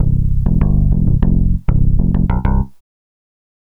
Swinging 60s 2 Bass-C.wav